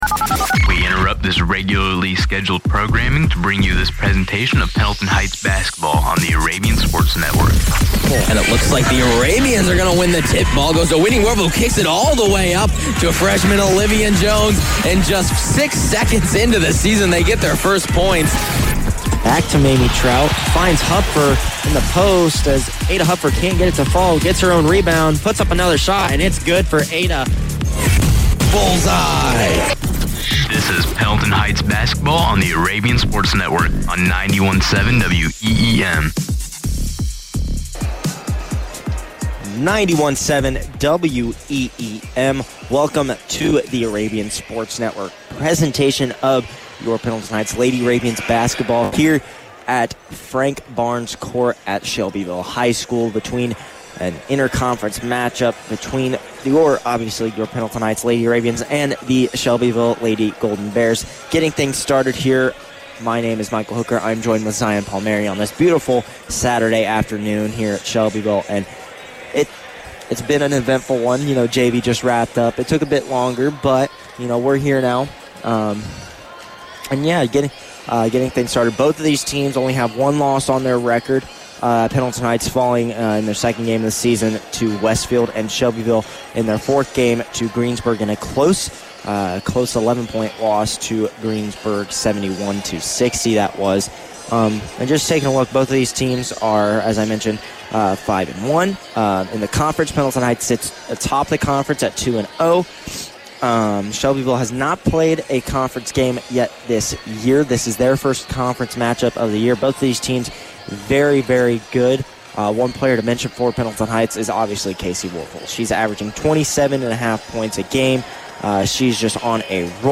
Varsity Girls Basketball Broadcast Replay Pendleton Heights vs. Shelbyville 11-25-23